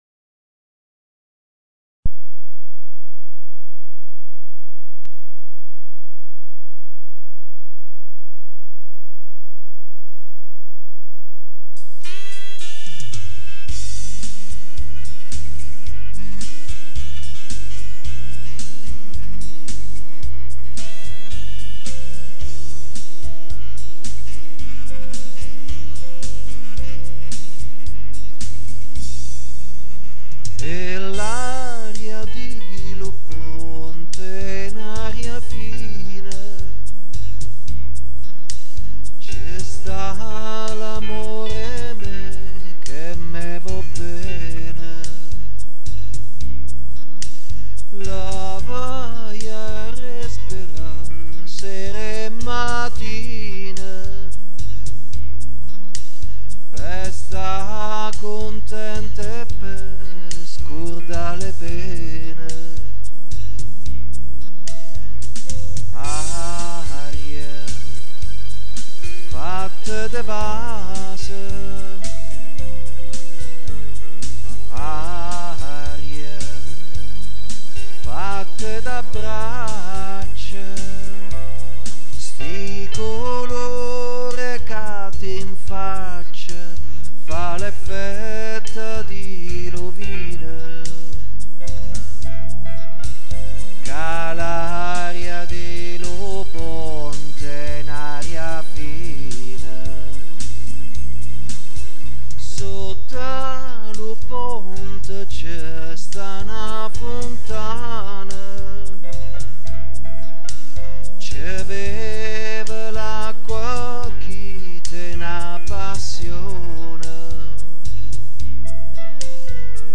canzone popolare celebrativa del suo paese.
laria_di_lu_ponte-canto.mp3